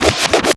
BOW N ARROW.wav